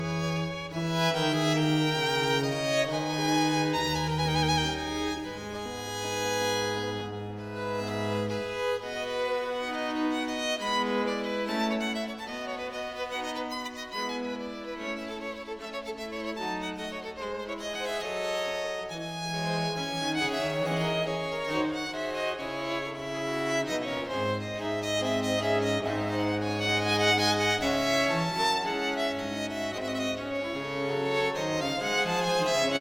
Generating music from a written description: “Create something in the style of a chaconne for violin.”